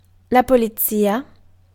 Ääntäminen
Ääntäminen France: IPA: [pɔ.lis] Haettu sana löytyi näillä lähdekielillä: ranska Käännös Konteksti Ääninäyte Substantiivit 1. polizza {f} 2. polizia {f} 3. madama {f} slangi Suku: f .